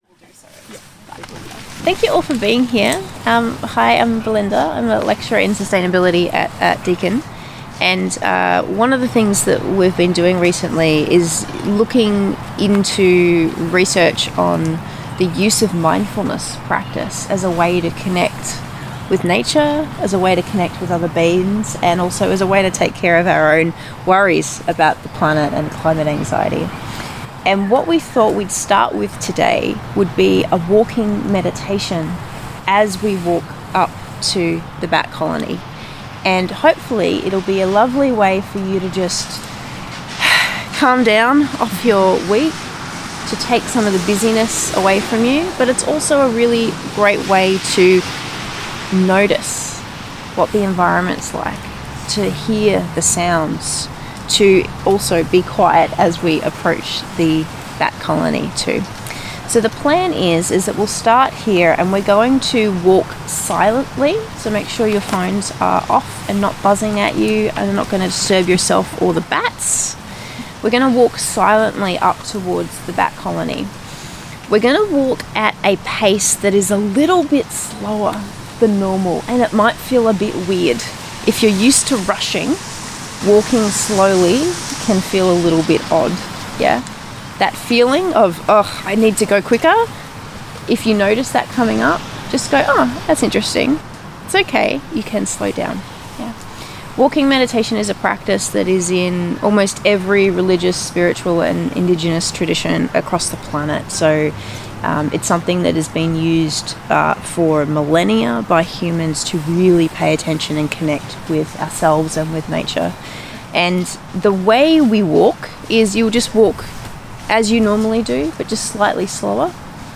Through guided nature walks and close listening, the artwork opens up new ways of relating – not just to bats, but to place, to time, and to each other.
Nature meditation walk in March 2026